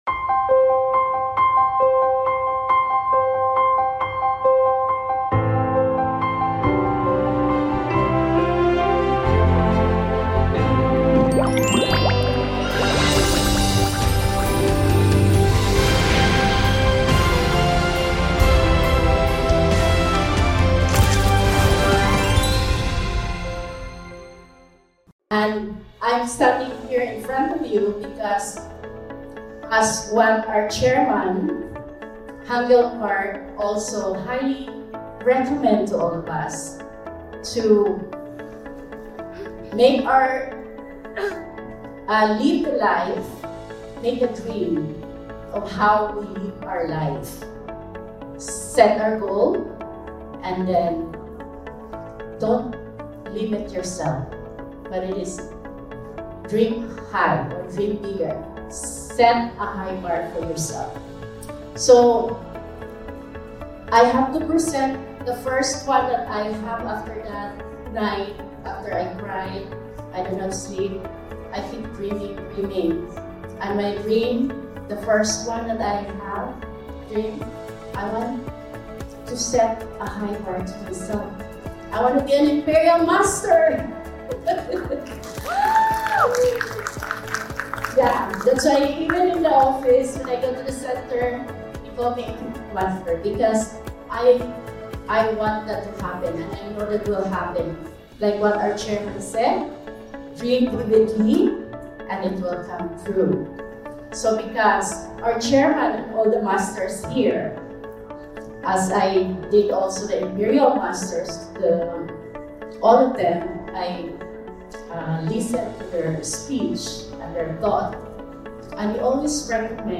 Life Scenario Presentation
Vancouver Success Academy